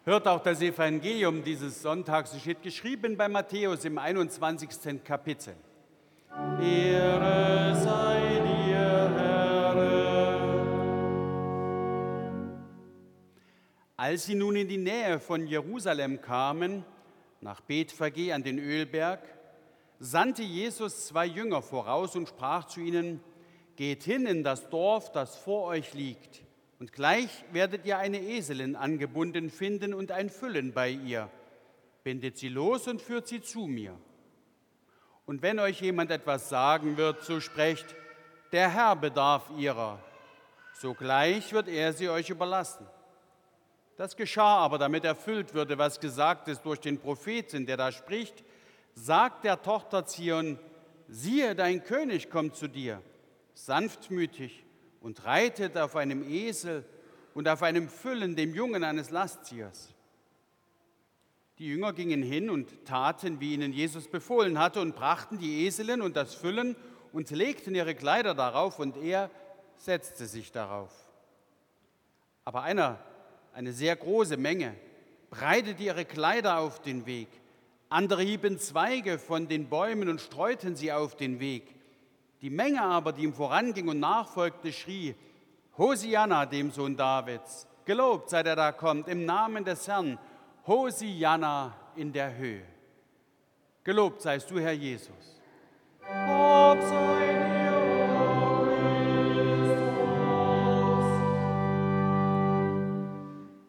Evangeliumslesung aus Matthäus 21,1-9 Ev.-Luth.
Audiomitschnitt unseres Gottesdienstes vom Palmsonntag 2026.